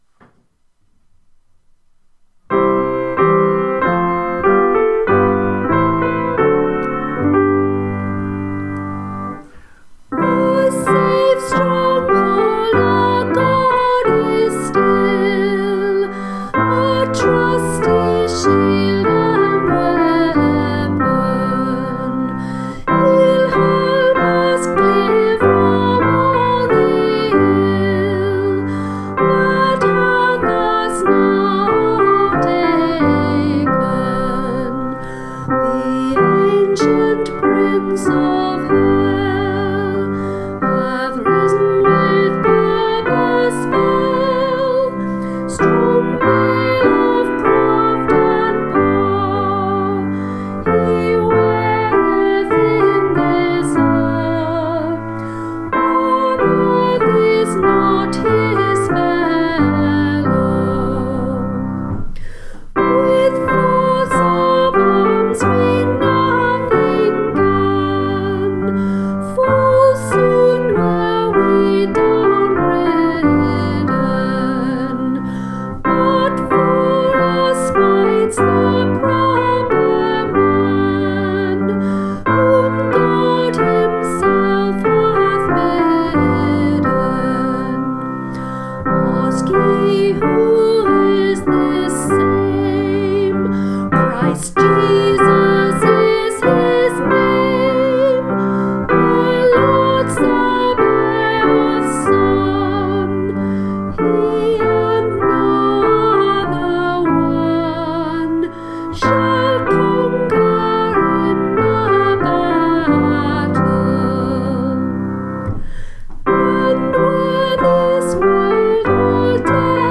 Piano
Singing